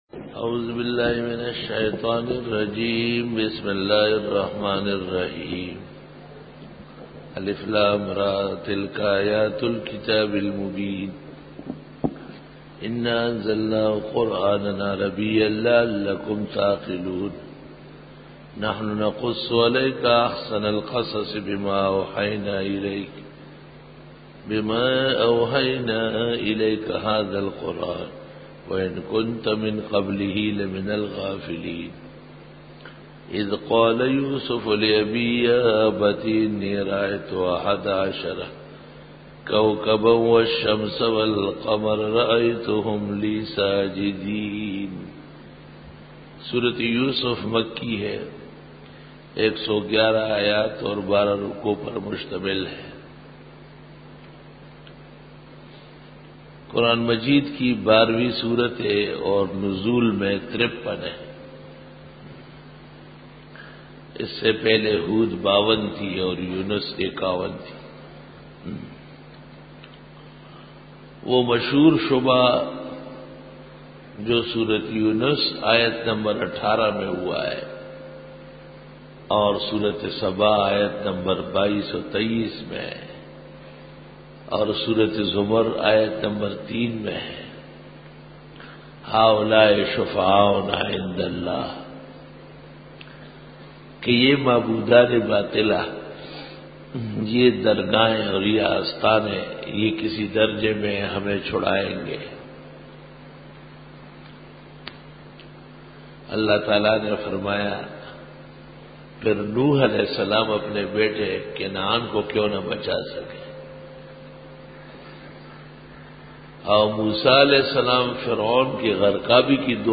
سورۃ یوسف رکوع-01 Bayan